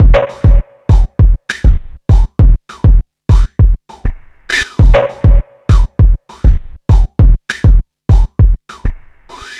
noise beat 100bpm 02.wav